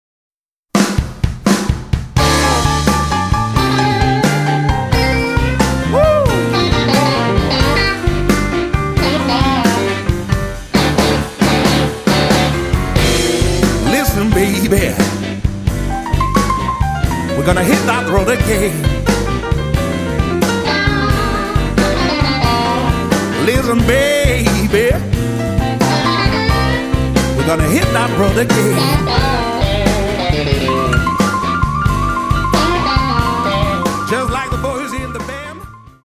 vocal, guitar
organ, piano
harp
bass
drums